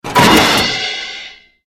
metal_joint_break_01.ogg